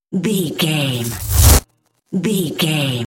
Trailer raiser
Sound Effects
Fast paced
In-crescendo
Atonal
bouncy
driving
futuristic
tension
riser